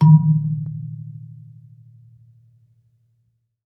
kalimba_bass-D#2-mf.wav